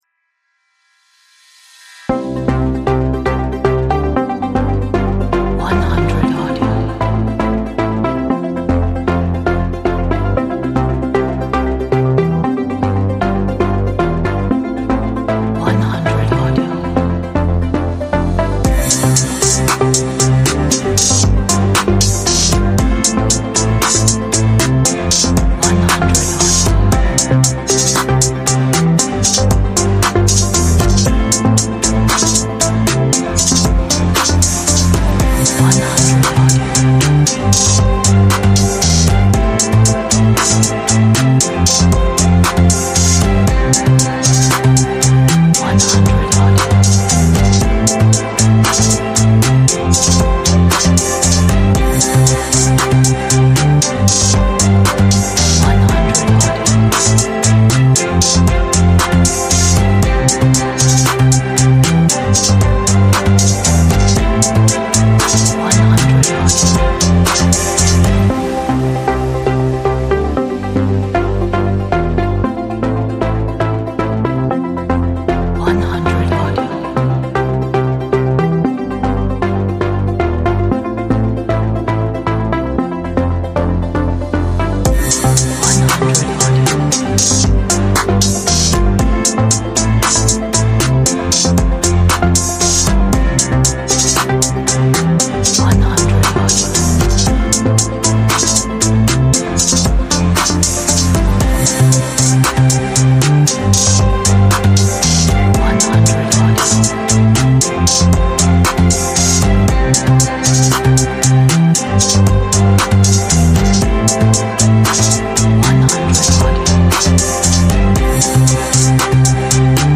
a nice pop upbeat inspirational 这是一首很好的鼓舞人心流行乐